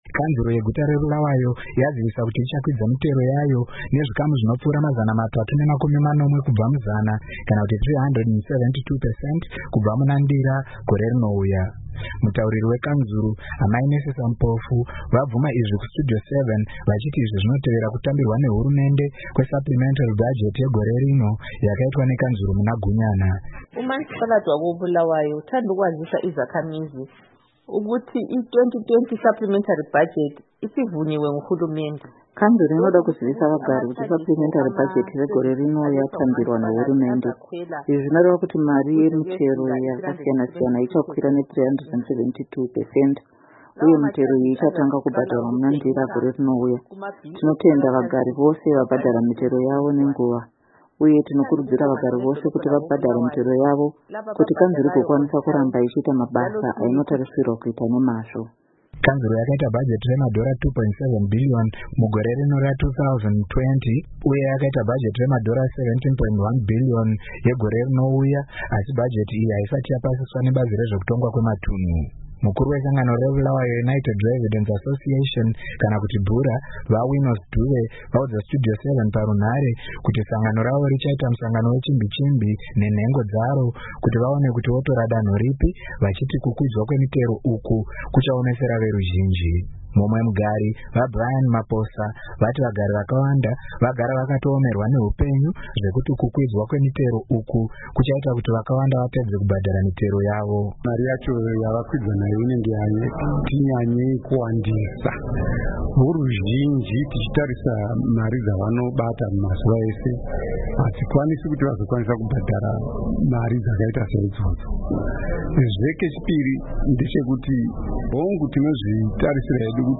vaudza Studio 7 parunhare kuti sangano ravo richaita musangano wechimbi-chimbi nenhengo dzaro kuti vaone kuti votora danho ripi